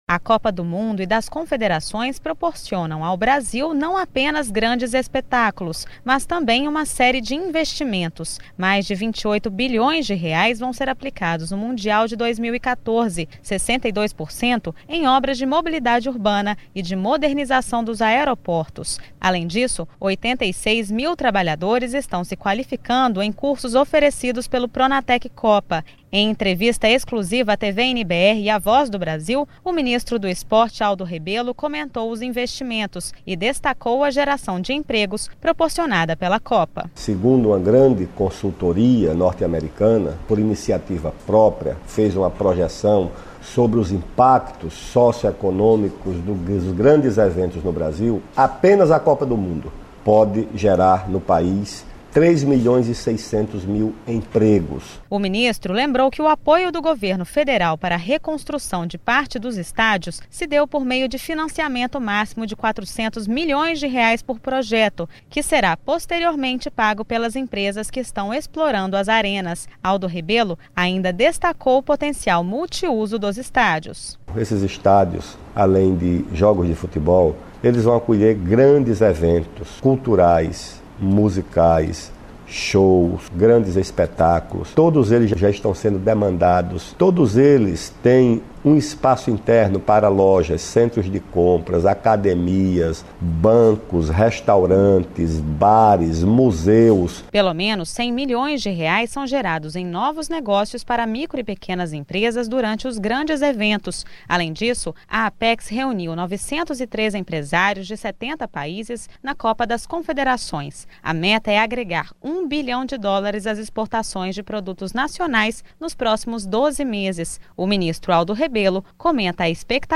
Em entrevista exclusiva à Voz, Aldo Rebelo fala sobre investimentos em grandes eventos